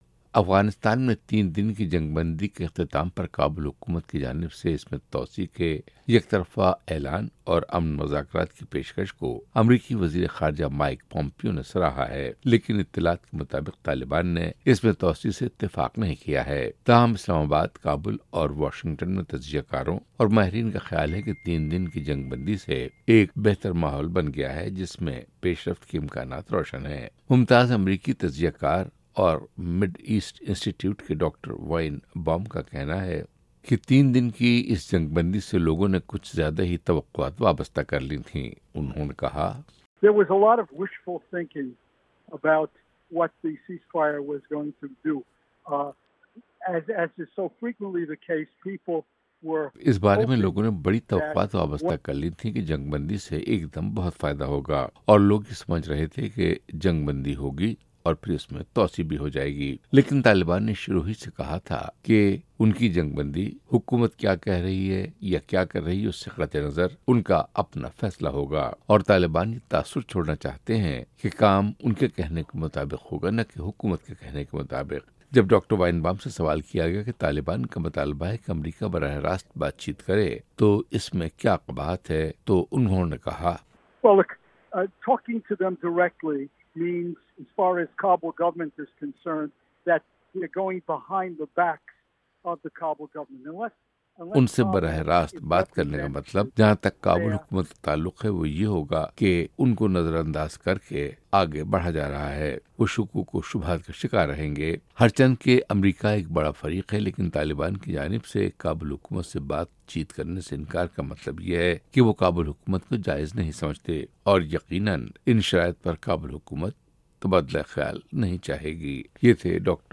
اس بارے میں ماہرین سے